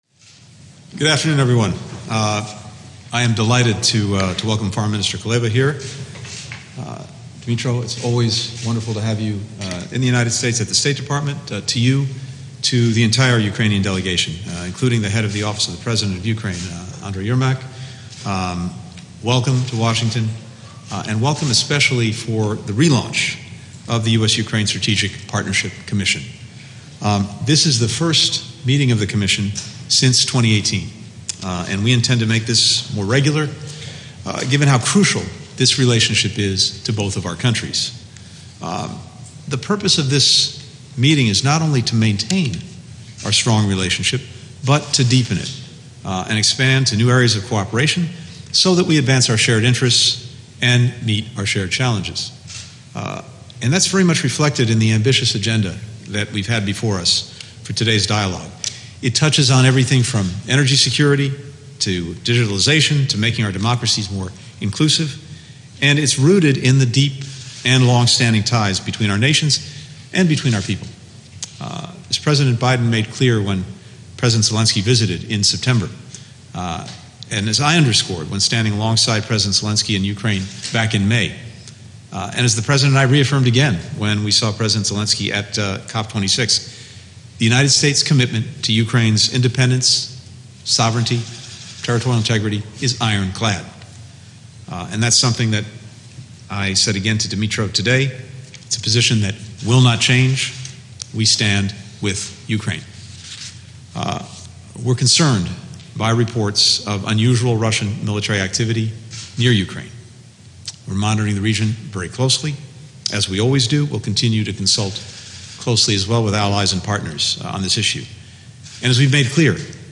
Antony Blinken Press Conference with Dmytro Kuleba (transcript-audio-video)